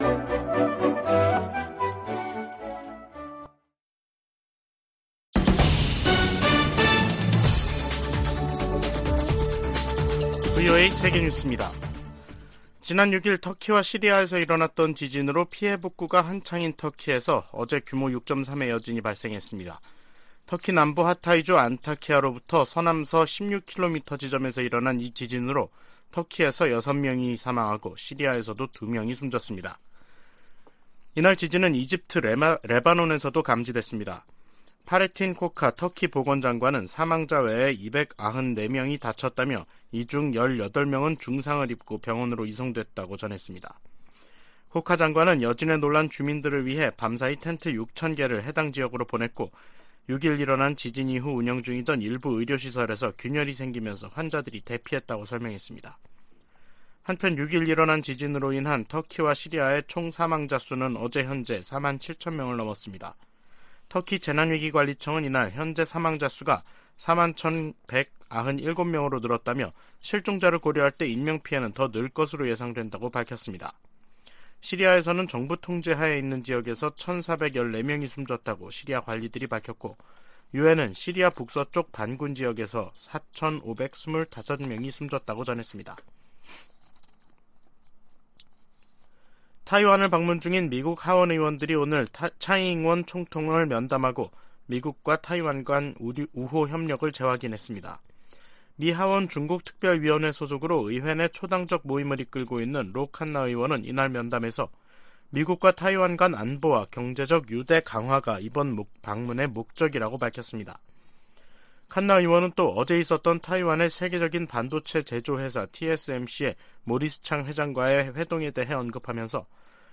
VOA 한국어 간판 뉴스 프로그램 '뉴스 투데이', 2023년 2월 21일 3부 방송입니다. 유엔 안전보장이사회가 북한의 대륙간탄도미사일(ICBM) 발사에 대응한 공개회의를 개최한 가운데 미국은 의장성명을 다시 추진하겠다고 밝혔습니다. 김여정 북한 노동당 부부장은 ‘화성-15형’의 기술적 문제점을 지적하는 한국 측 분석들을 조목조목 비난하면서 민감한 반응을 보였습니다. 북한이 ICBM급에도 대기권 재진입 기술을 확보했다는 관측이 확대되고 있습니다.